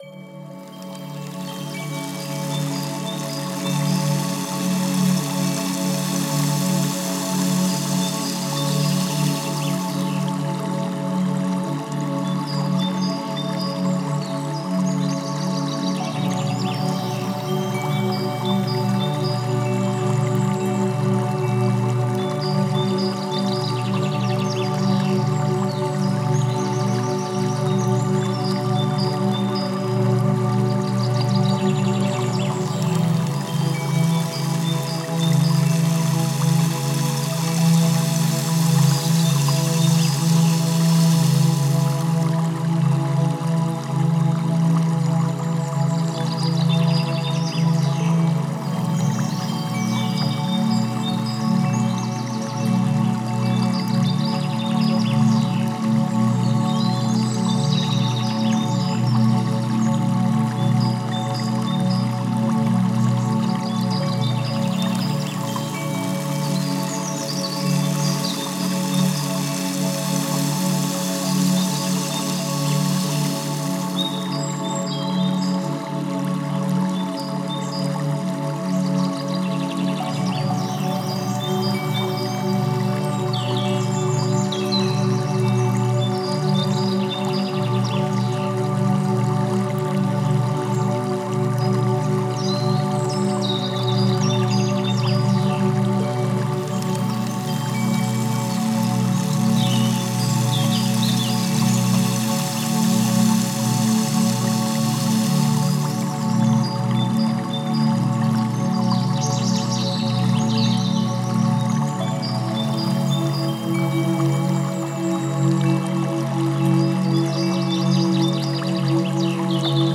With tools like interactive games, motivational videos, and relaxing music, you’ll find everything you need to stay engaged and motivated.
meditation-in-nature.mp3